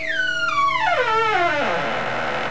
home *** CD-ROM | disk | FTP | other *** search / Horror Sensation / HORROR.iso / sounds / iff / sqweek10.snd ( .mp3 ) < prev next > Amiga 8-bit Sampled Voice | 1992-12-21 | 50KB | 1 channel | 19,886 sample rate | 2 seconds
sqweek10.mp3